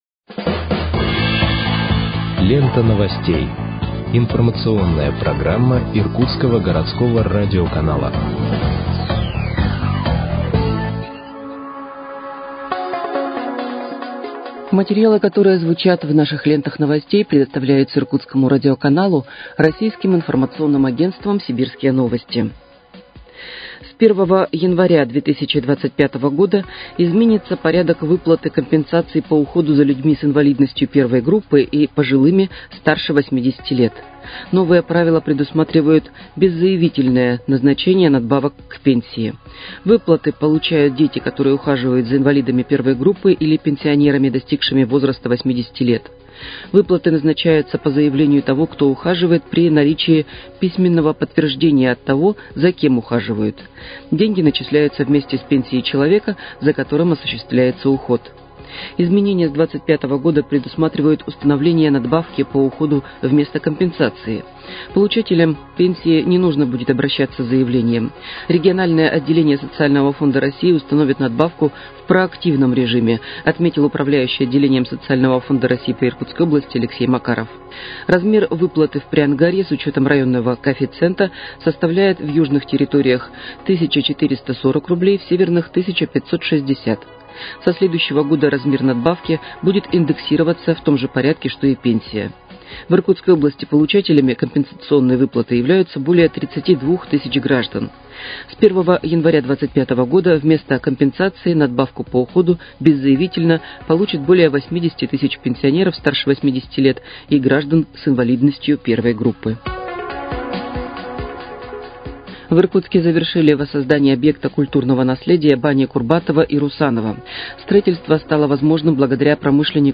Выпуск новостей в подкастах газеты «Иркутск» от 05.12.2024 № 2